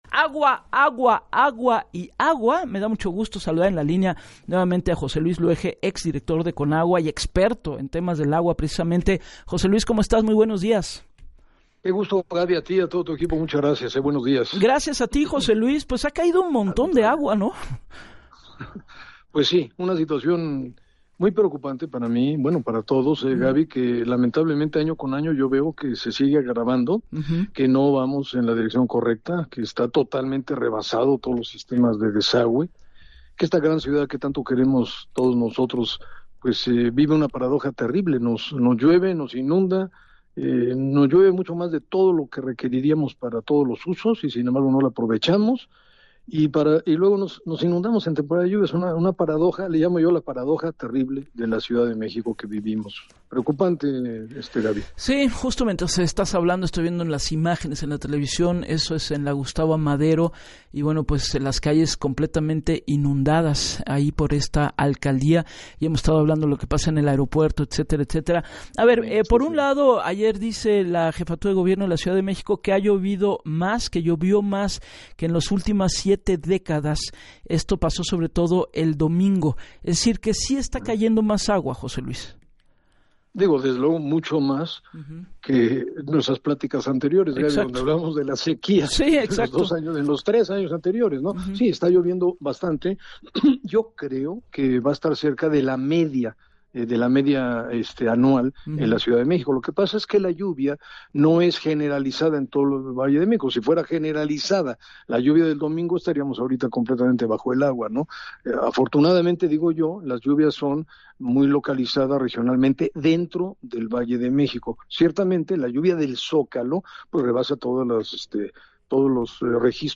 En entrevista para Así las Cosas con Gabriela Warkentin, el ex director de la Comisión Nacional del Agua, recordó que en años anteriores se hablaba de sequía, pero en esta temporada, aunque la lluvia no es generalizada, en el Valle de México se han registrado precipitaciones extraordinarias y el Centro Histórico rompió récord de acumulación de agua.